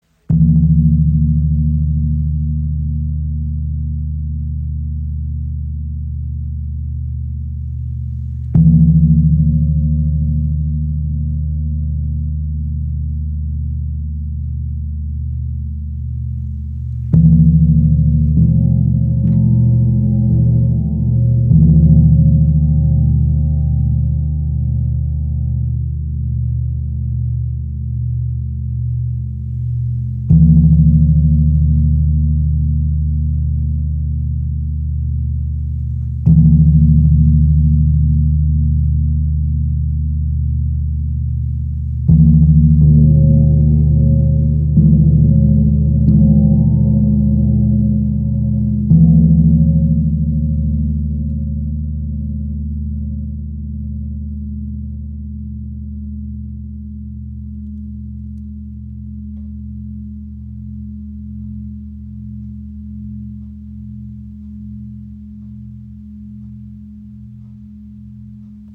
Buckelgong | Bali | ø 70 cm | ~E im Raven-Spirit WebShop • Raven Spirit
Klangbeispiel
Buckelgongs sind charakteristisch für die Musik Südostasiens und strahlen eine besonders kraftvolle Präsenz aus. Ihre kennzeichnende Wölbung in der Mitte, auf der sie meist angeschlagen werden, verleiht ihnen einen warmen, satten und ruhigen Klang. Im Vergleich zum Tam-Tam Gong schwillt ihr Klang nach dem Anschlagen nicht auf, sondern entwickelt einen gedämpften und schwebenden Ton.